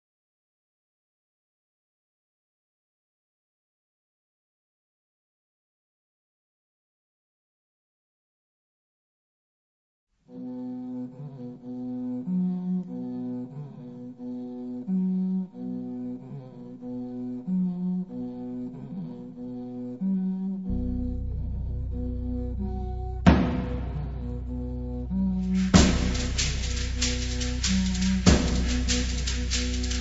atupan drums
bells, vocals
berimbau
brekete drums
congas, wea flute
cowbells, cabasa
• registrazione sonora di musica